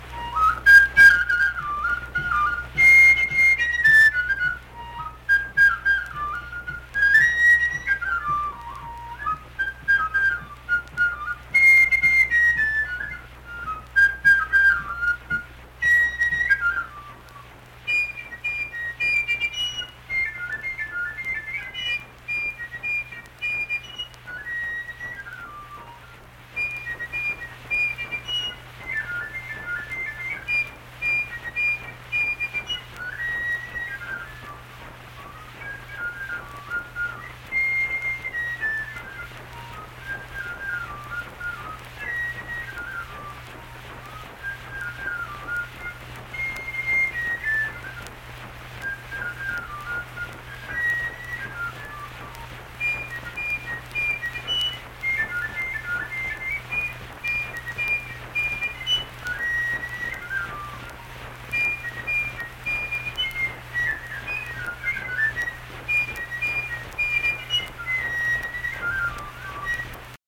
Unaccompanied fife music
Performed in Hundred, Wetzel County, WV.
Instrumental Music
Fife